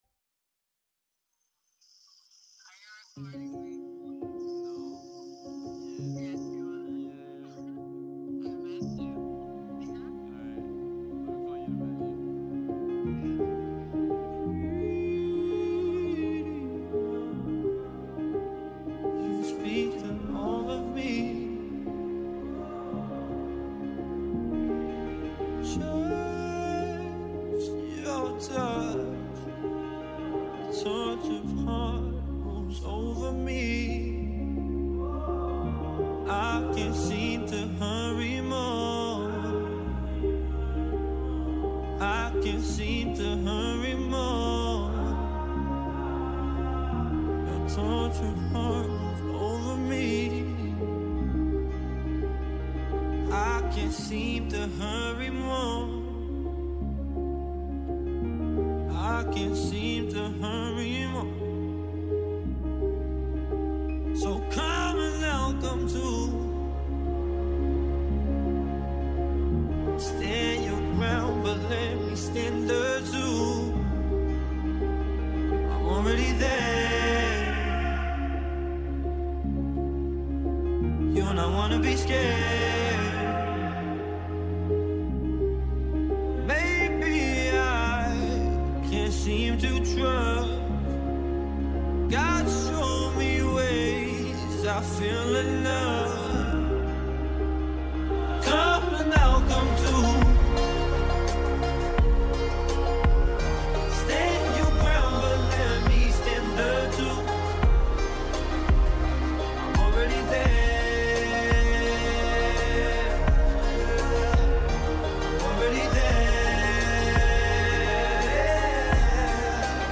Καλεσμένος σήμερα στην εκπομπή ο χαρισματικός Good Job Nicky.